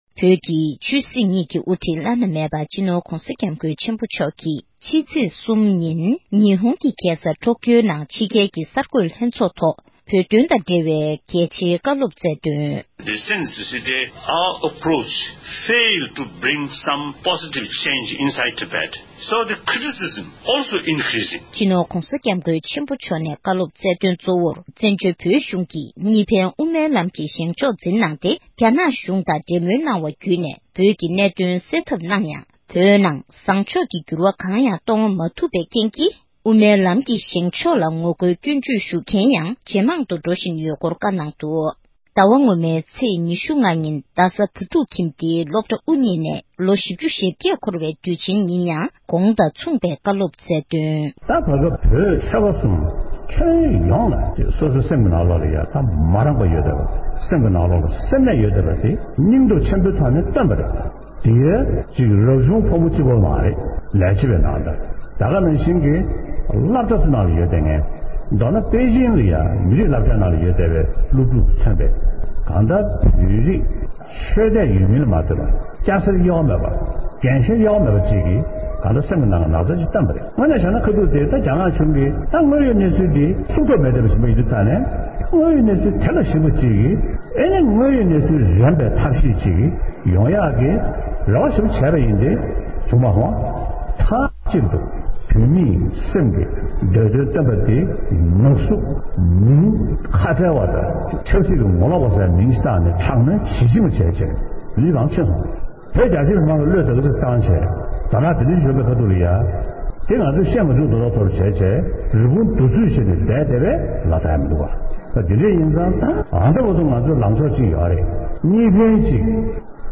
༸གོང་ས་མཆོག་གིས་ཉི་ཧོང་གསར་འགོད་ལྷན་ཚོགས་ཐོག་བཀའ་སློབ།
སྒྲ་ལྡན་གསར་འགྱུར། སྒྲ་ཕབ་ལེན།